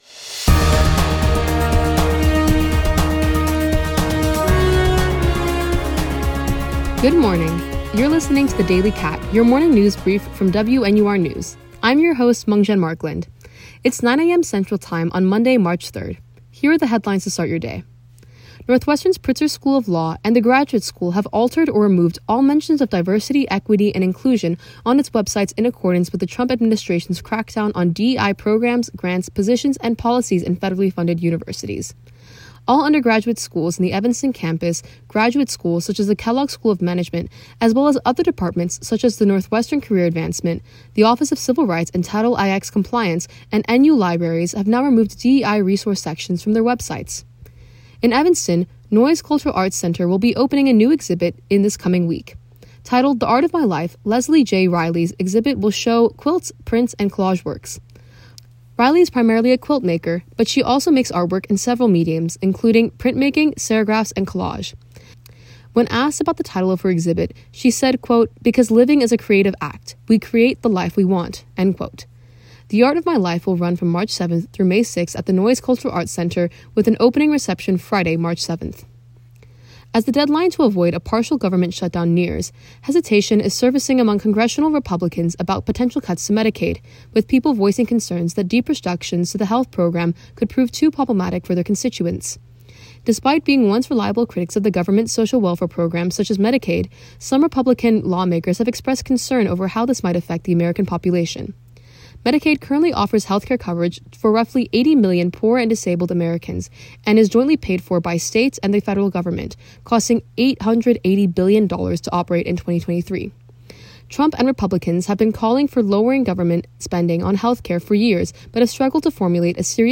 WNUR News’ Daily Briefing – March 3, 2025: Removing DEI resources from university websites, new art exhibit at Noyes Cultural Arts Center, Republicans conflicted over Medicaid. WNUR News broadcasts live at 6 pm CST on Mondays, Wednesdays, and Fridays on WNUR 89.3 FM.